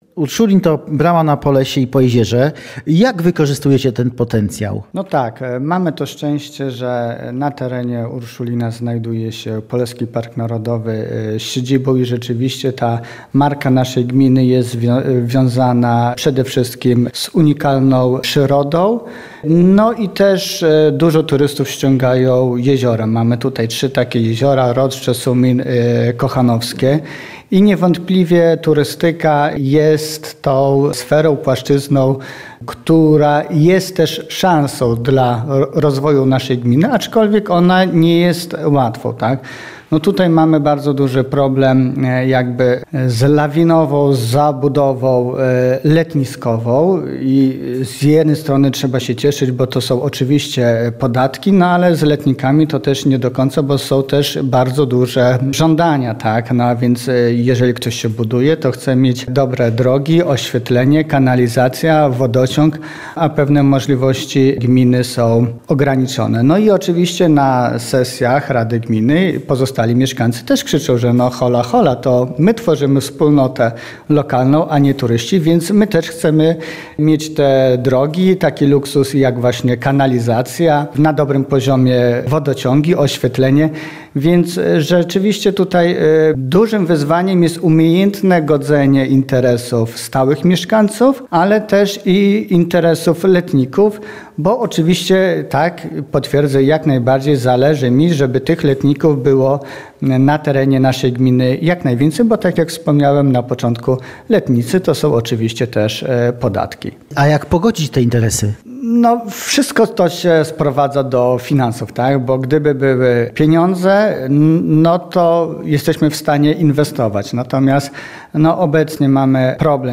O potencjale historycznym i turystycznym gminy, a także próbie pogodzenia interesów mieszkańców i letników z wójtem gminy Urszulin Adamem Panasiukiem rozmawia